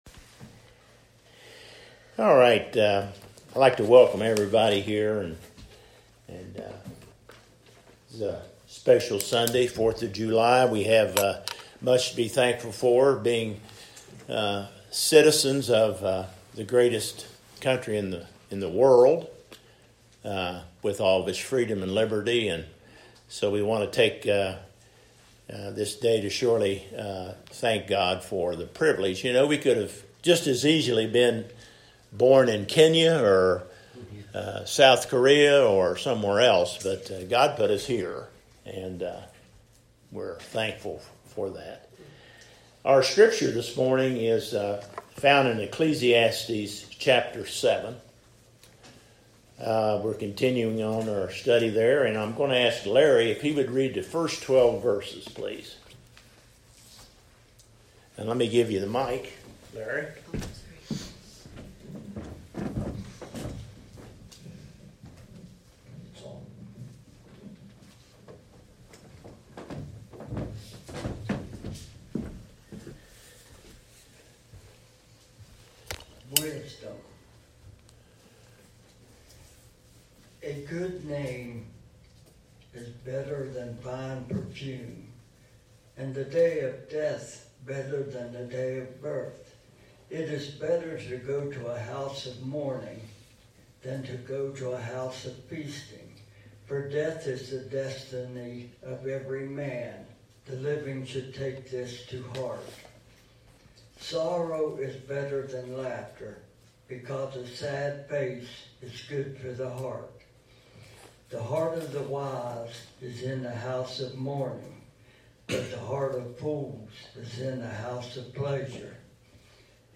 edited-sermon.mp3